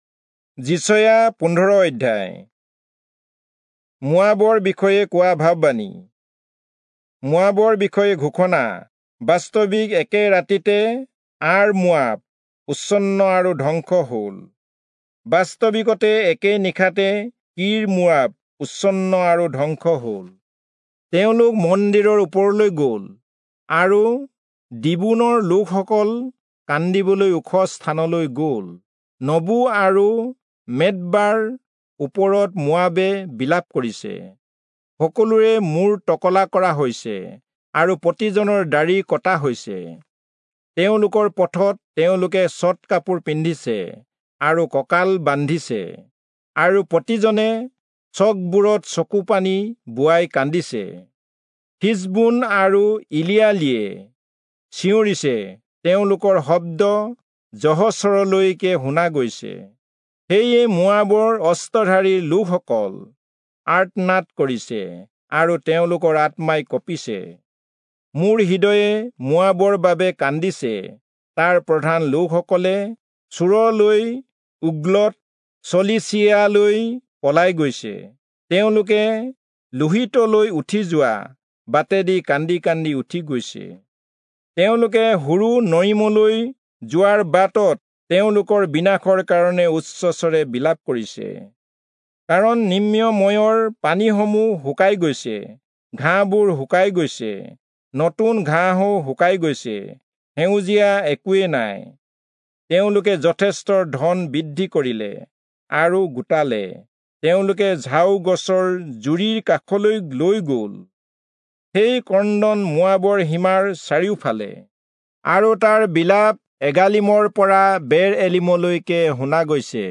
Assamese Audio Bible - Isaiah 51 in Tov bible version